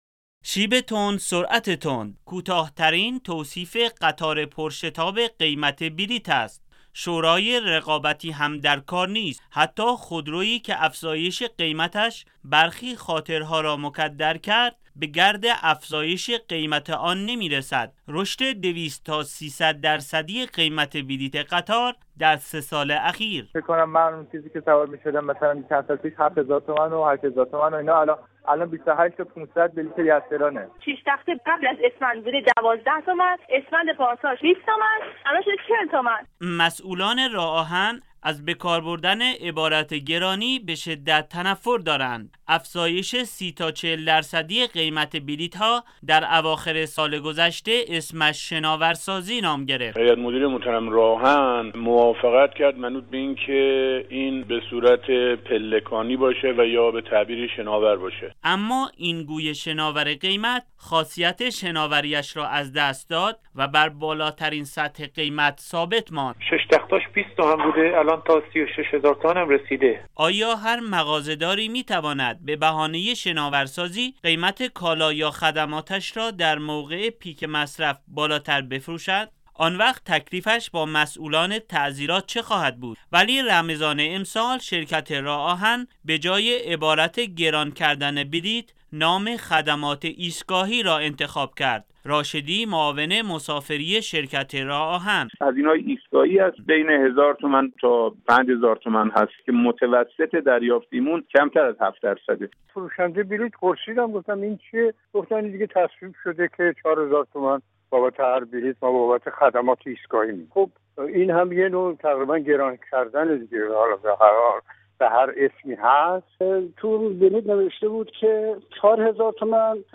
گزارش "شنیدنی" ؛ افزایش چندباره بلیط قطار و بی‌تفاوتی مسئولان - تسنیم